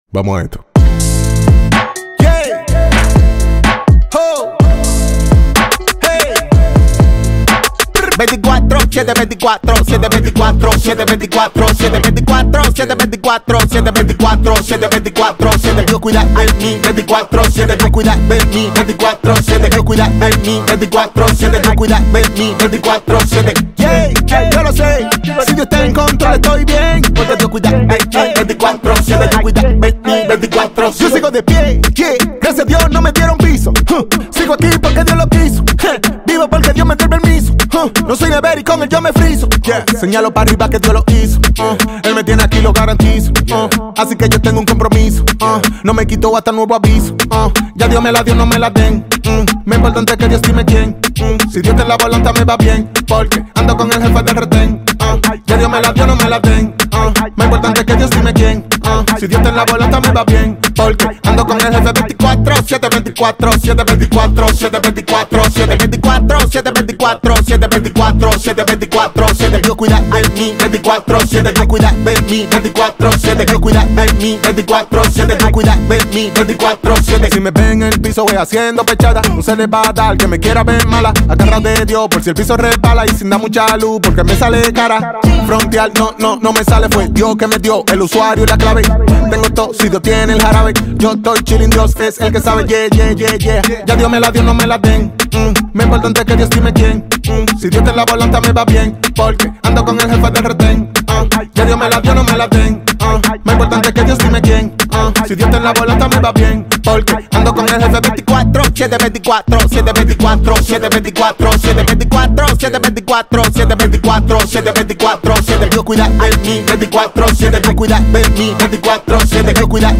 Universal Gospel
a mesmerising melody